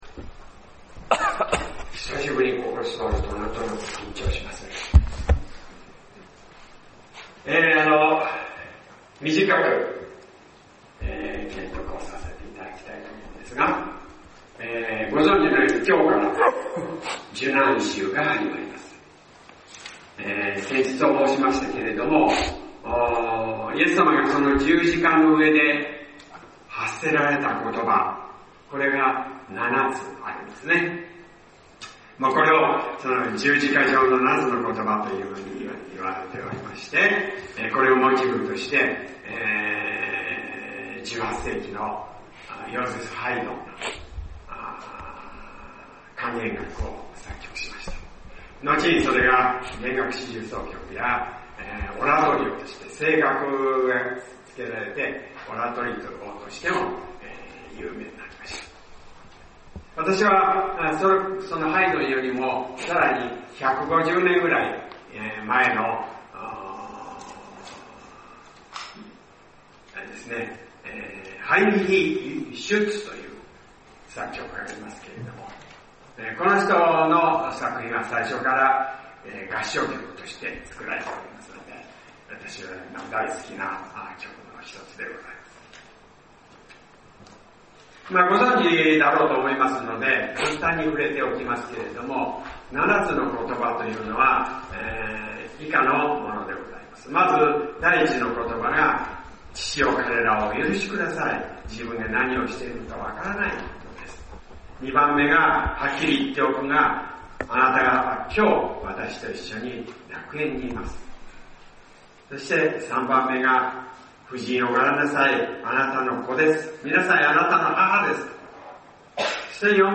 先週，東京集会で行わ れた礼拝で録音された建徳です。